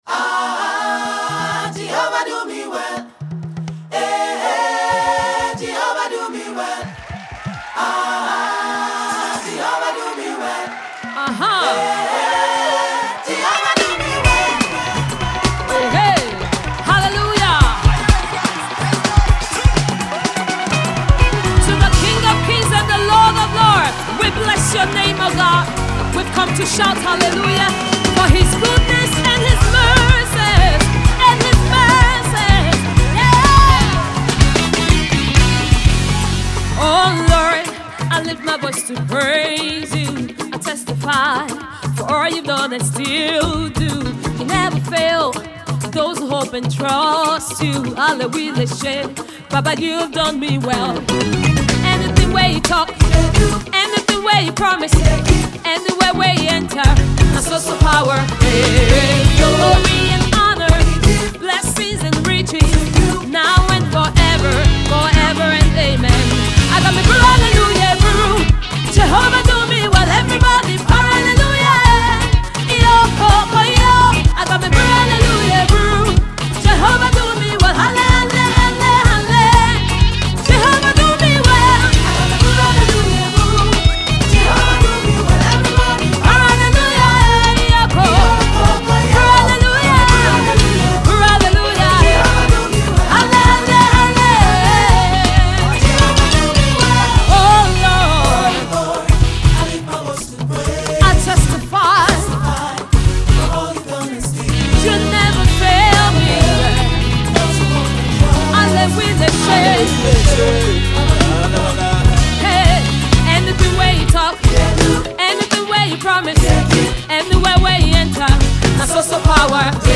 Gospel music
The song is Afrocentric with west African percussion.